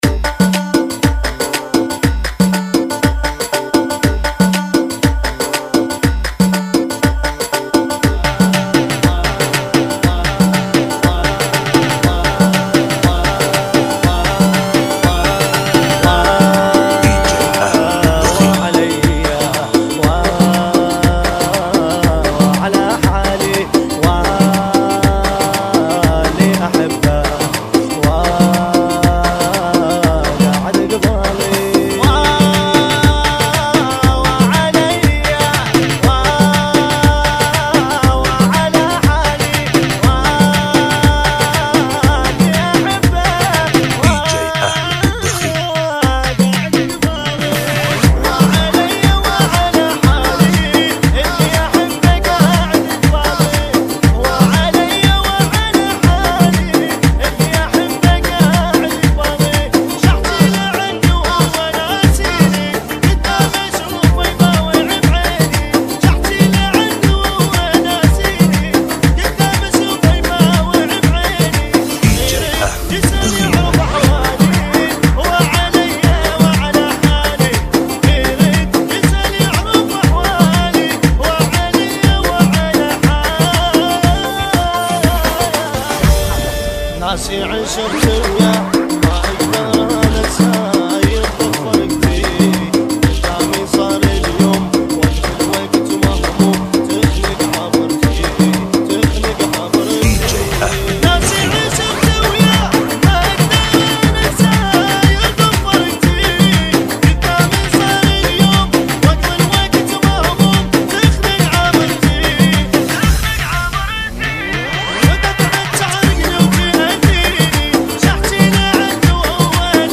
ريمكس
Funky Remix